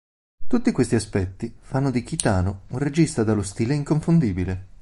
Read more (masculine) style (masculine) class Frequency B1 Hyphenated as stì‧le Pronounced as (IPA) /ˈsti.le/ Etymology Borrowed from French style, itself borrowed from Latin stilus.